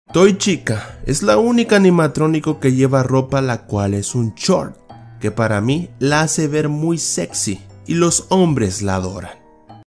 toy chica es la unica animatronico Meme Sound Effect